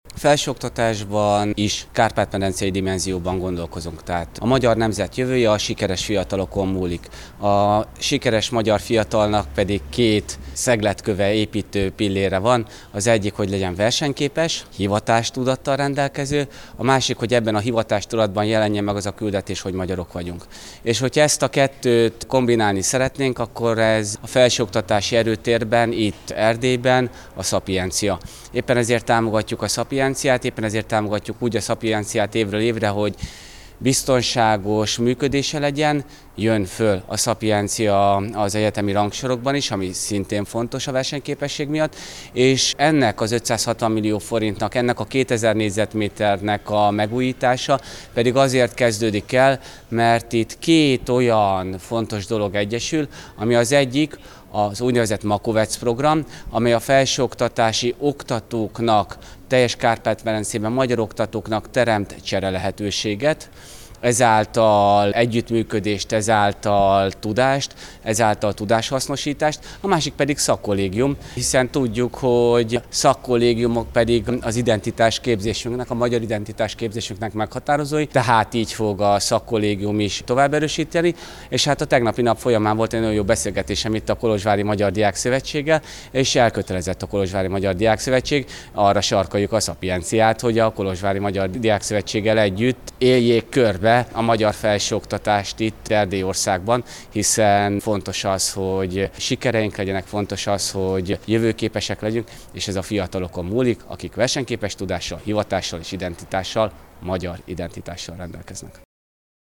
Az ingatlan felújítását a Magyar Kormány 560 millió forinttal támogatja – jelentette be ma Hankó Balázs Kultúráért és Innovációért felelős miniszter, az egyetem vezetőségével tartott közös sajtótájékoztatón.